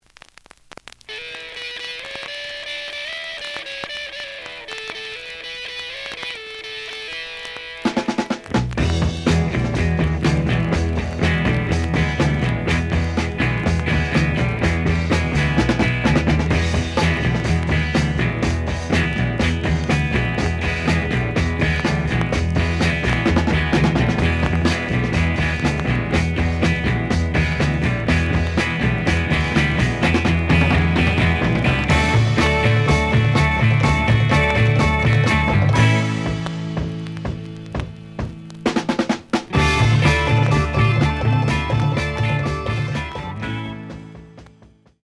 The audio sample is recorded from the actual item.
●Genre: Rock / Pop
Some click noise on B side due to scratches.